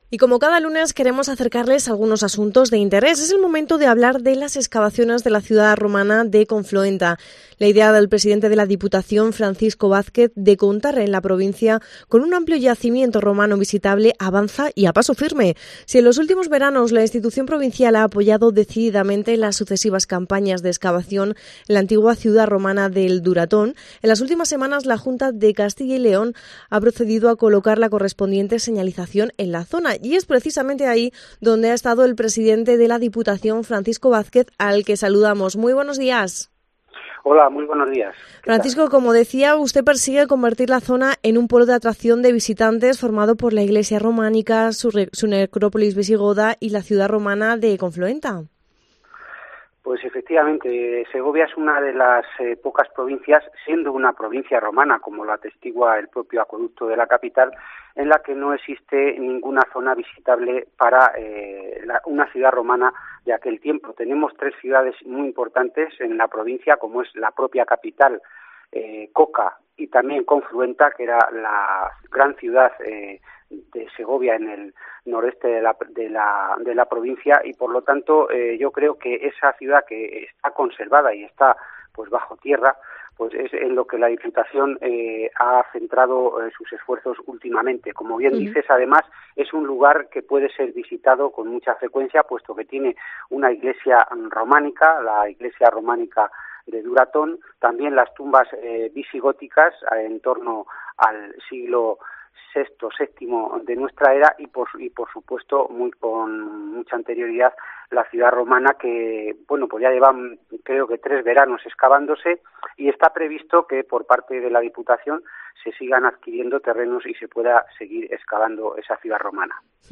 Entrevista a Francisco Vázquez, Presidente de la Diputación Provincial. Hablamos sobre el proyecto de un amplio yacimiento Romano visitable en Segovia.